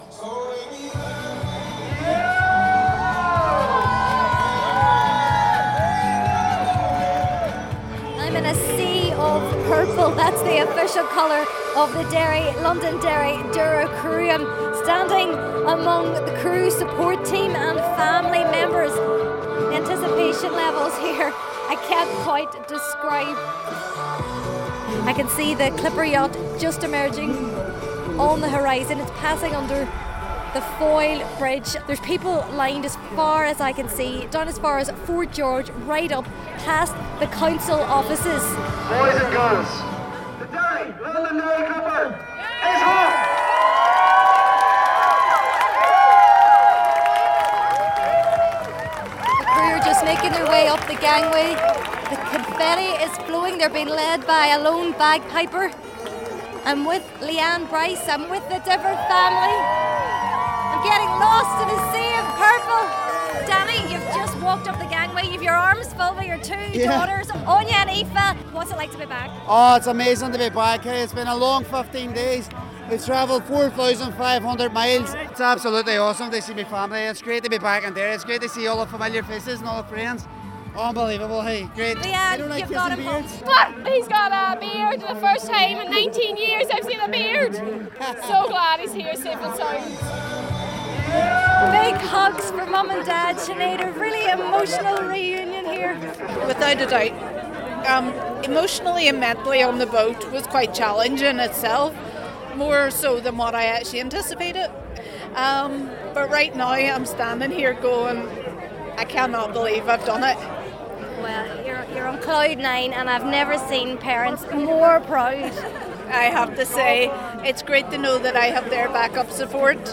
LISTEN - Clipper celebrations in Derry